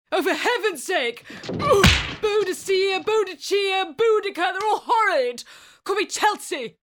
I have a naturally husky, sexy mid tone.
and something to make you smile.. Mother Theresa and Boudica pop in!